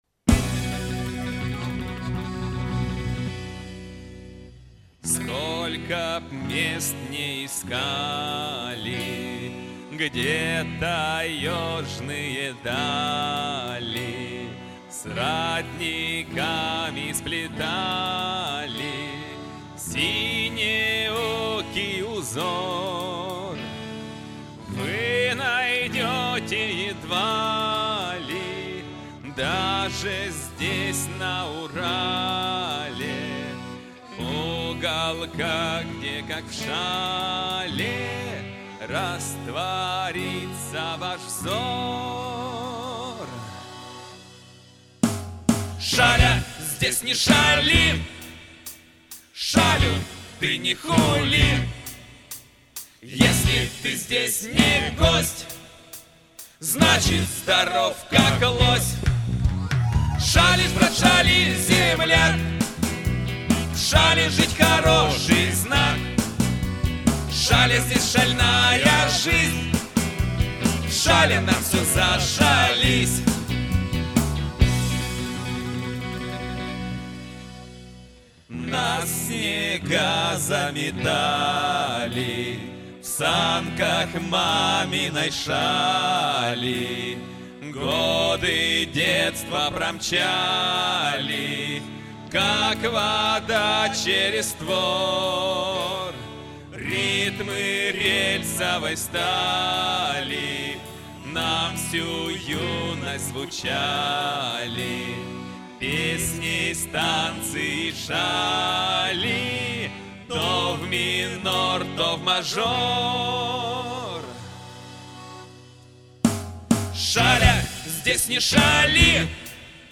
бас-гитара, вокал
ударные
клавиши, ритм-гитара, вокал
бодрый припев